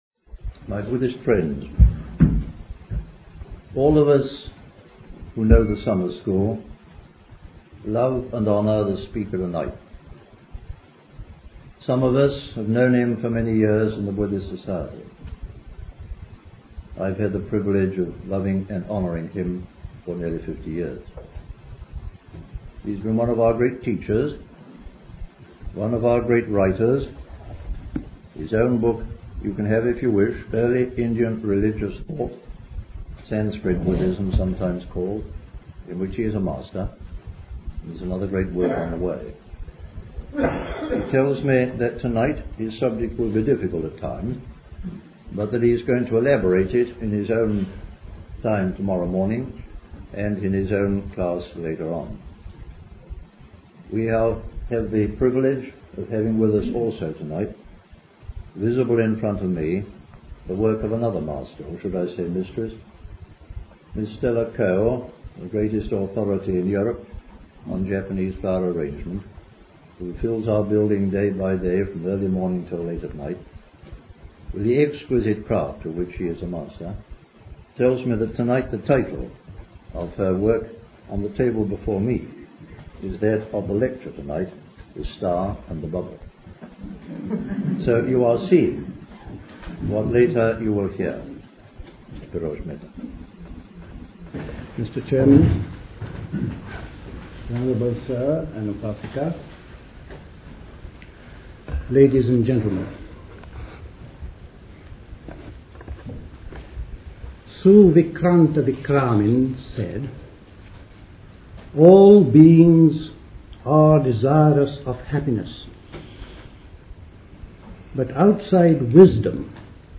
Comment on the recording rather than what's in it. Recorded at the August 1972 Buddhist Summer School.